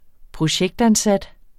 Udtale [ -anˌsad ]